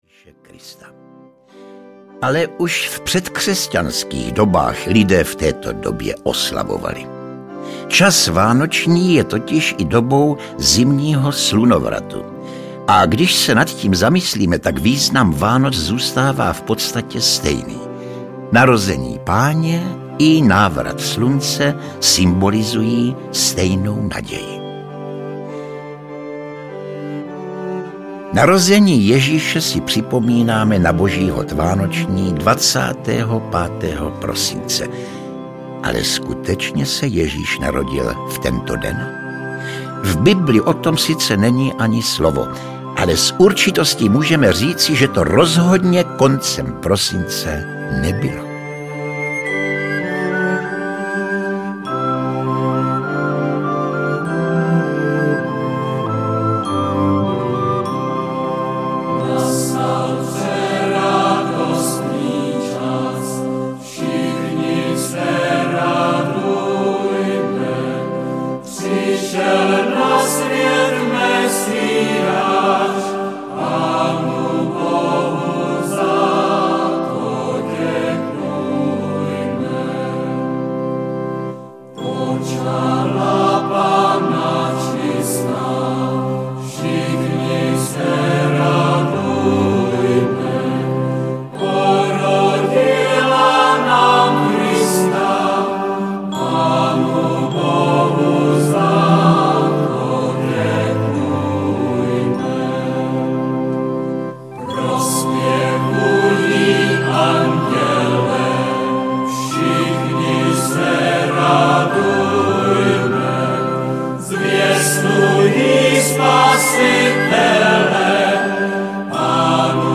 Oblíbený herec poutavě vypráví o významu jednotlivých svátků, o původu vánočního stromku i dárků, o vánočních pokrmech u nás i ve světě.
Ukázka z knihy
Povídání o vánočním stromku, vánočním kaprovi a tradičních i zapomenutých vánočních zvycích podbarvují nejznámější koledy. Špičkové hudební provedení jednotlivých koled a melodií podtrhuje jedinečnou atmosféru nahrávky.